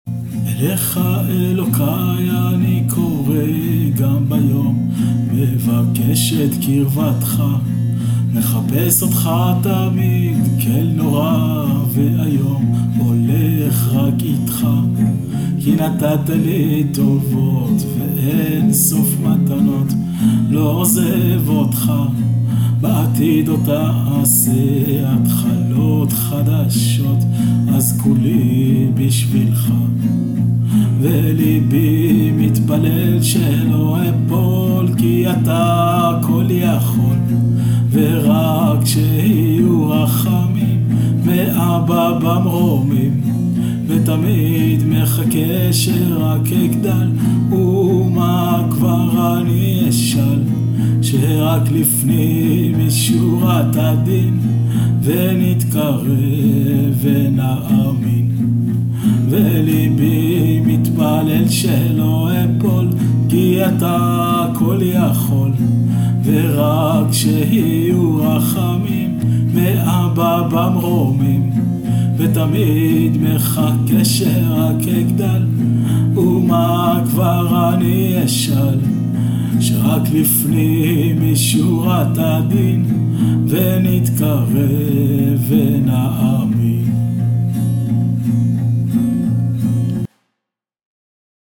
הכנסתי לסונו